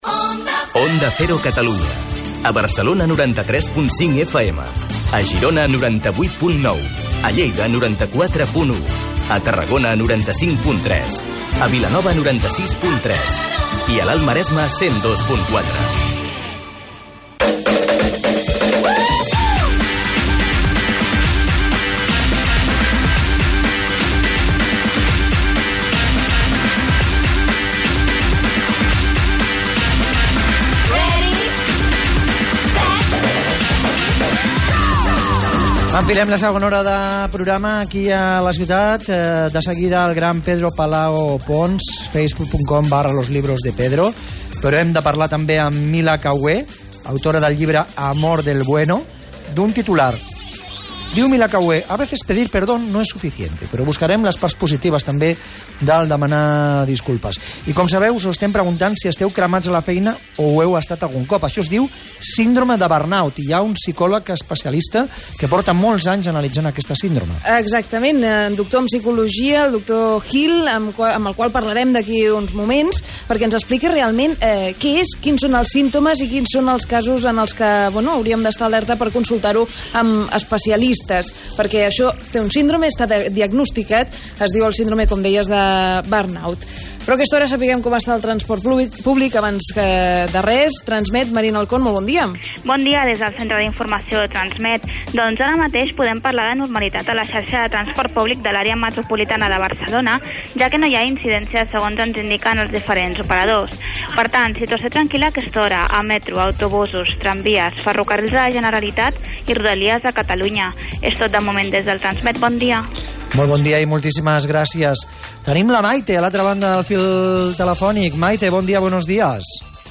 Mi intervención abarca del minuto 23:00 al 29:25